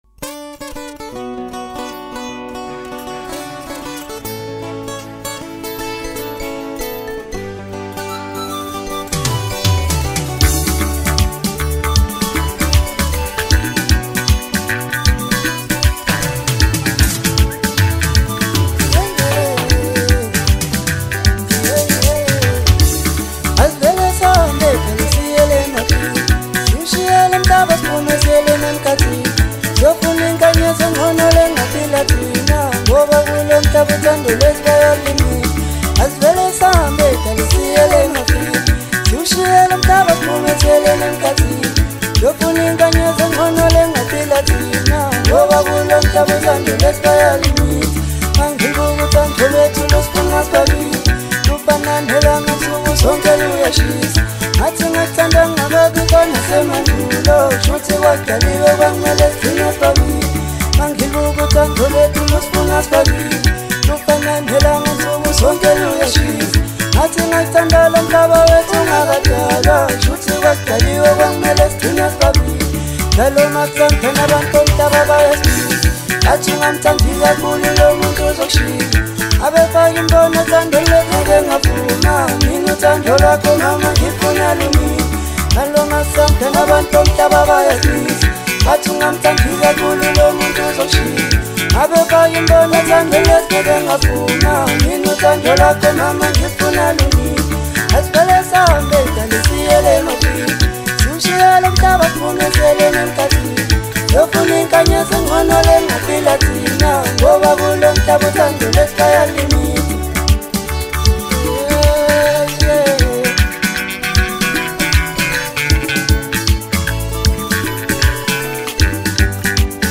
Maskandi
South African Maskandi singer-songwriter